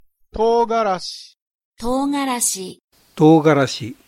Prononciation-de-togarashi.mp3